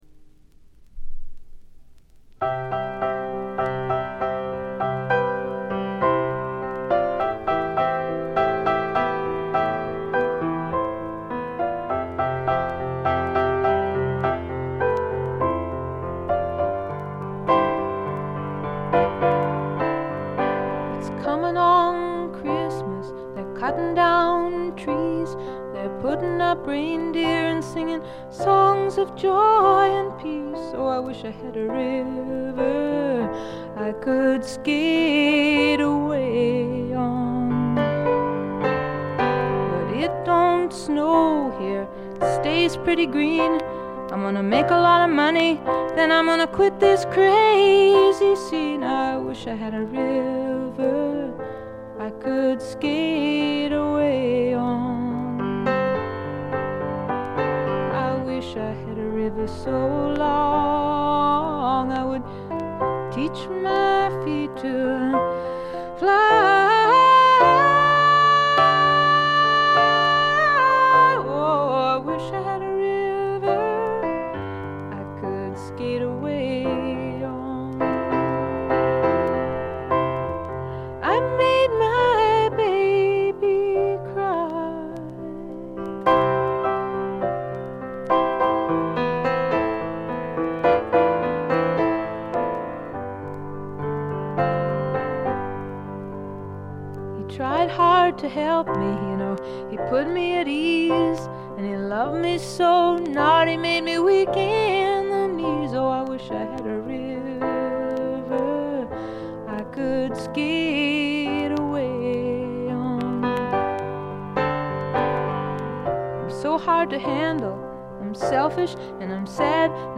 全体にバックグラウンドノイズ。細かなチリプチ多めですが、鑑賞を妨げるほどのノイズはないと思います。
試聴曲は現品からの取り込み音源です。